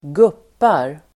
Ladda ner uttalet
Uttal: [²g'up:ar]